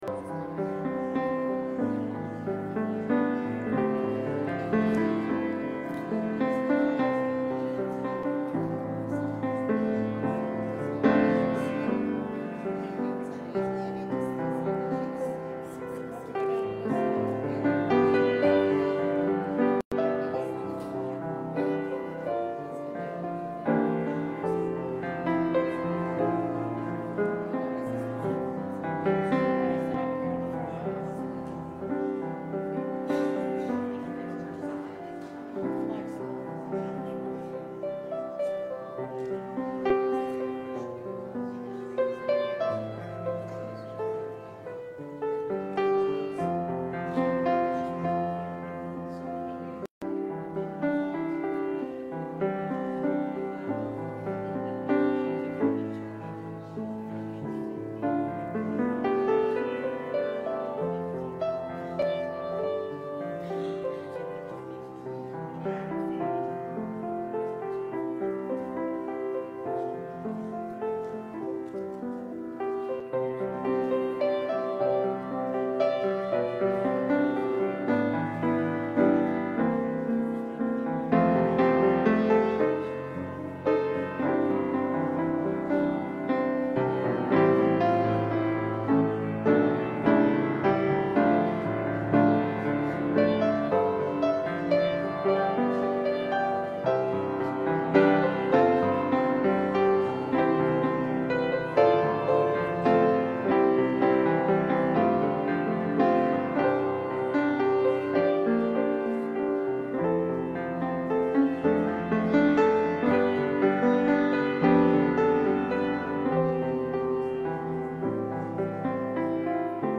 Passage: Colossians 3:23-25 Service Type: Morning « Another Epiphany